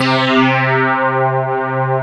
REZO PAD 1.wav